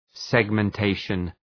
Shkrimi fonetik{,segmən’teıʃən}